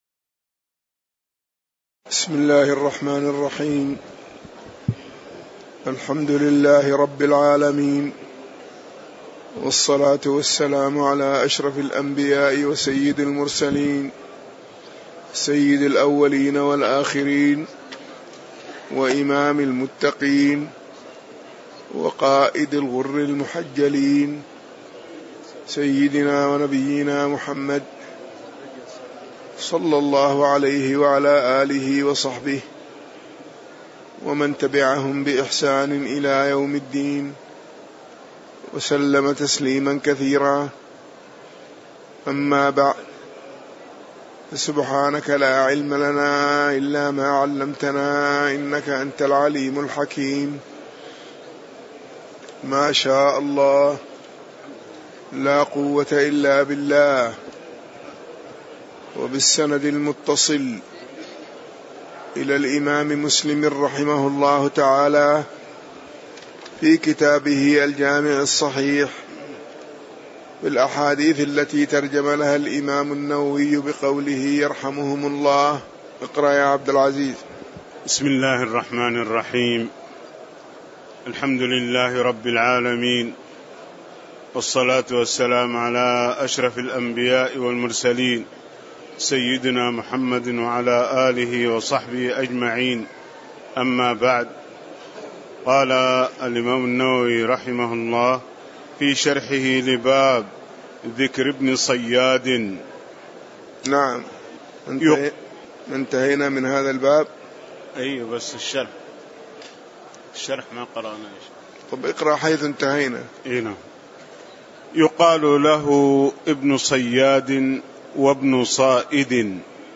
تاريخ النشر ٤ شعبان ١٤٣٨ هـ المكان: المسجد النبوي الشيخ